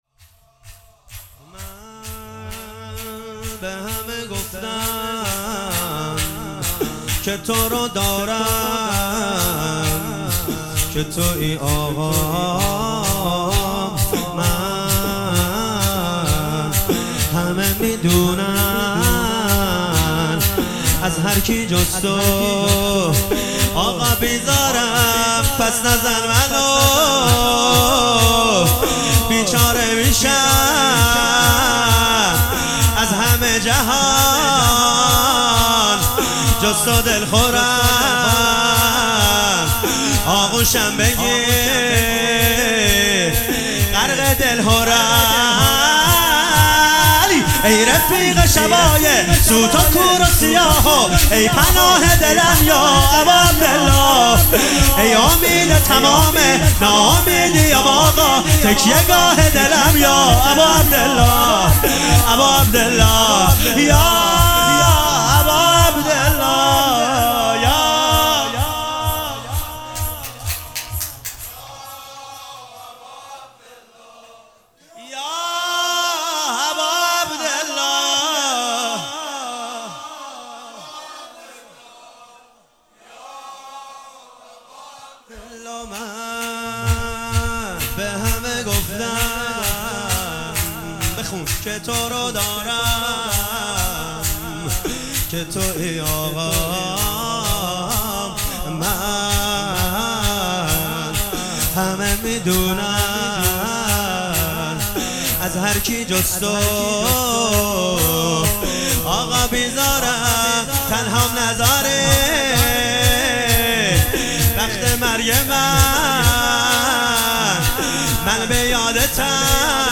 دسته : الکترونیک